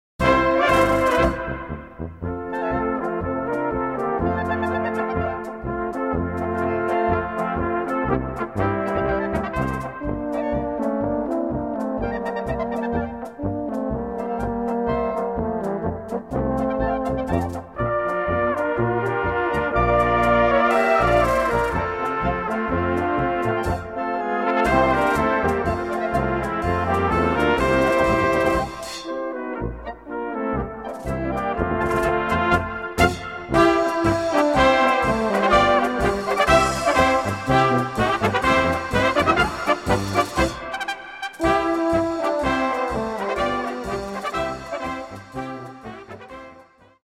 Gattung: Polka
Besetzung: Blasorchester